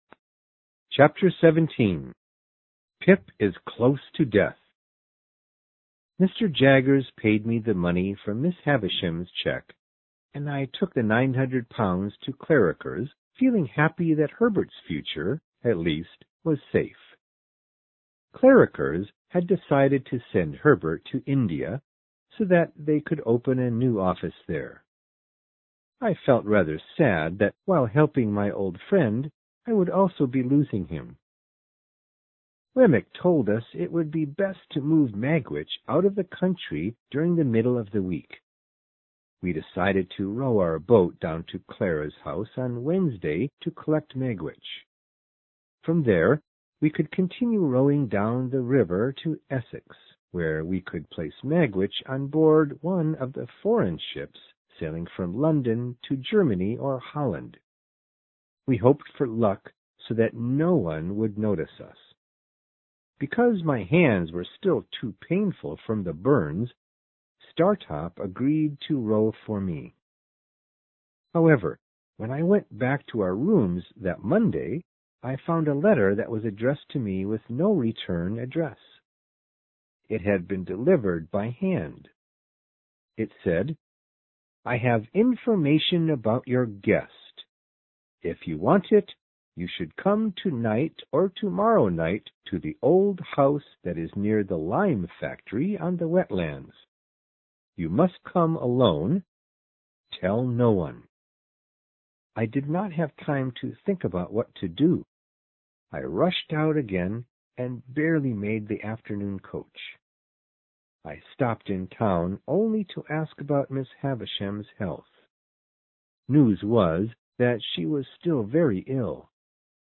有声名著之远大前程17 听力文件下载—在线英语听力室